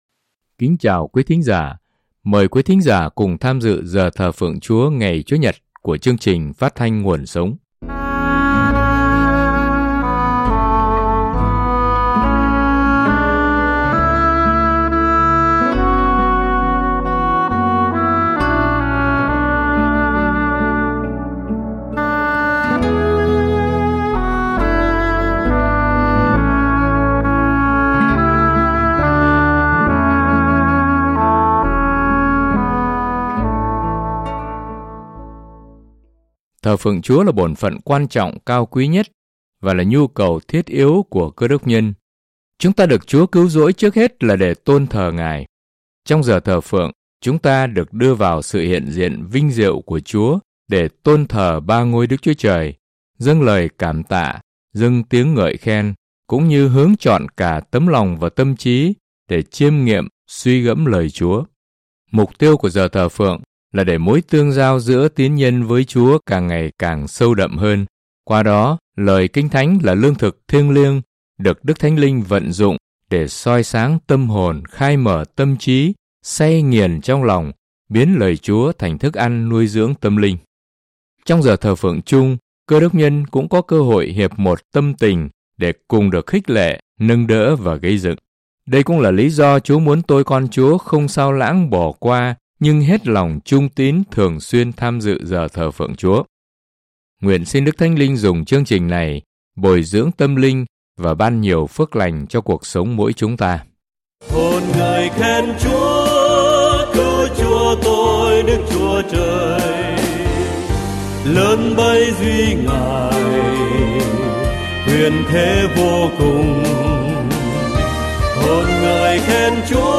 Chương trình: Thờ Phượng Giảng Luận